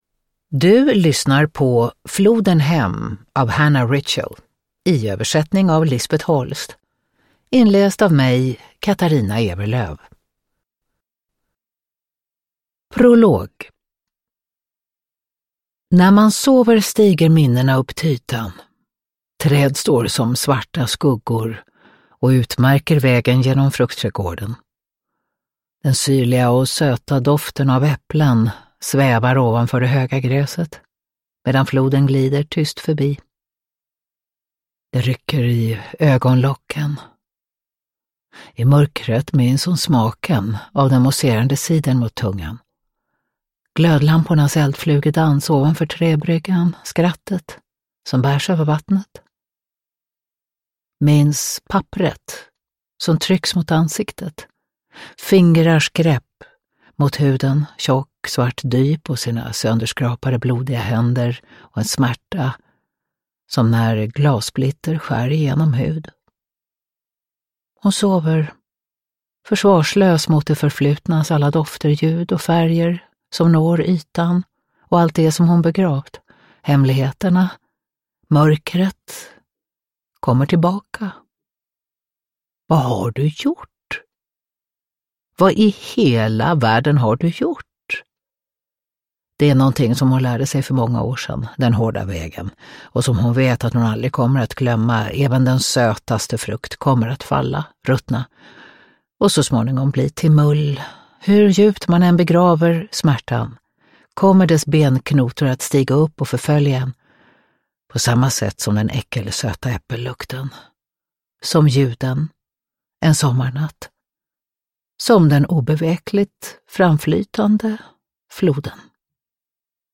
Floden hem – Ljudbok – Laddas ner
Uppläsare: Katarina Ewerlöf